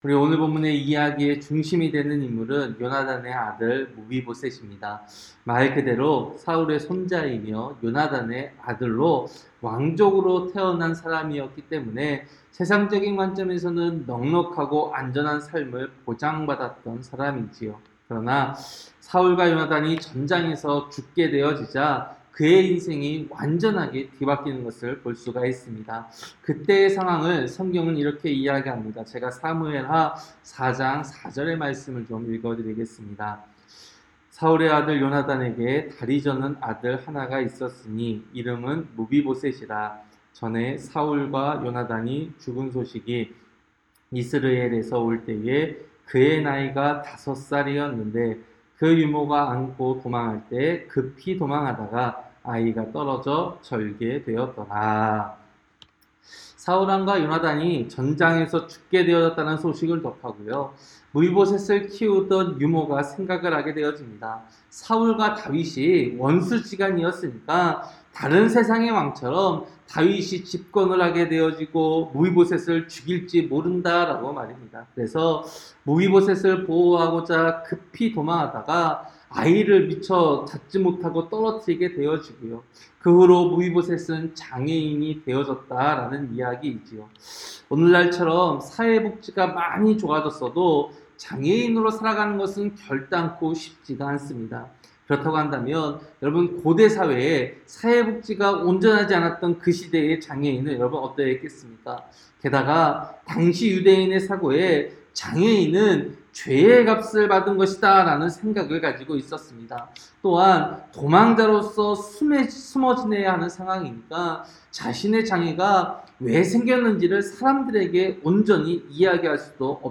새벽설교-사무엘하 9장